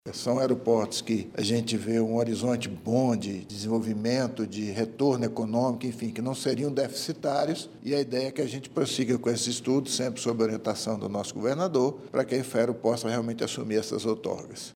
O presidente da Infraero, Rogério Barzellay, explica que a empresa observa alto potencial lucrativo nas estruturas do interior.
SONORA-2-INFRAERO-AEROPORTOS-INTERIOR-.mp3